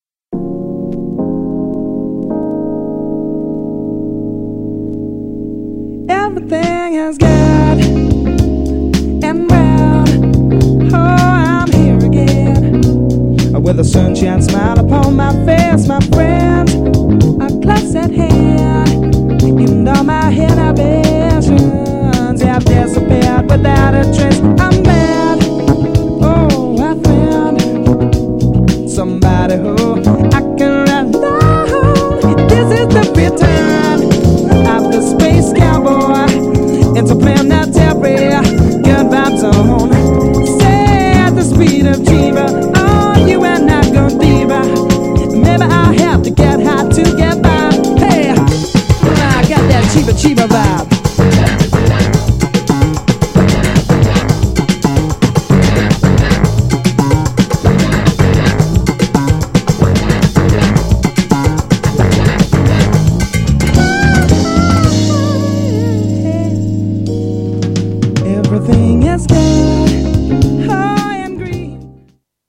オリジナルバージョンも収録。
GENRE House
BPM 116〜120BPM